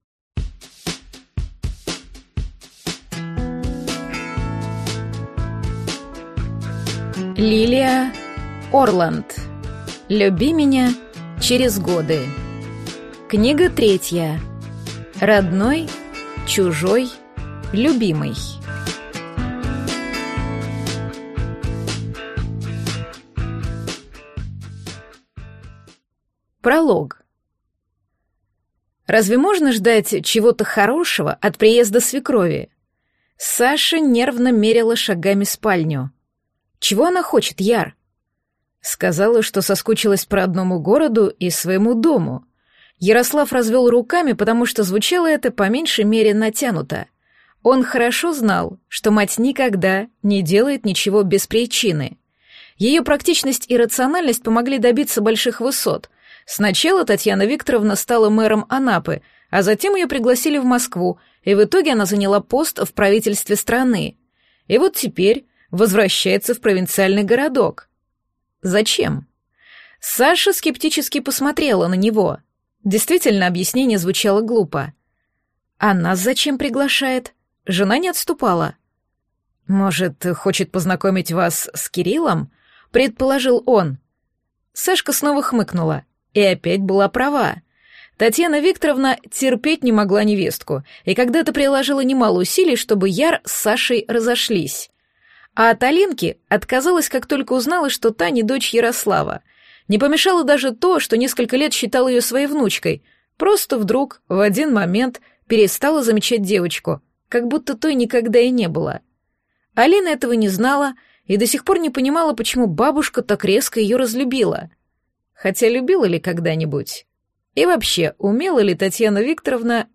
Аудиокнига Родной. Чужой. Любимый | Библиотека аудиокниг